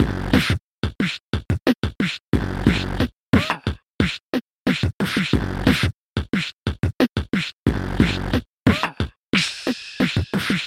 描述：Beatbox Loop 180 bpm
Tag: 180 bpm Drum And Bass Loops Beatbox Loops 1.79 MB wav Key : Unknown